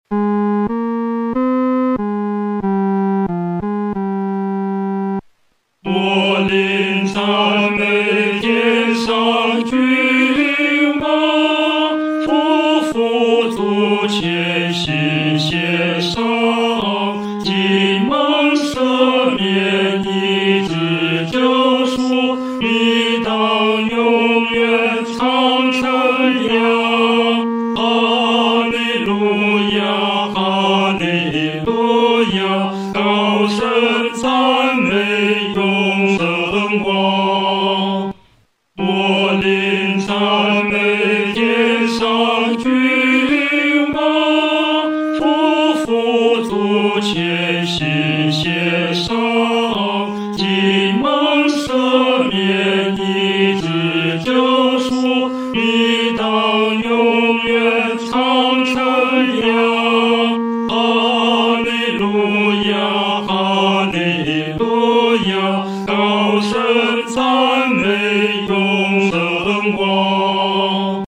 男高
本首圣诗由网上圣诗班 (南京）录制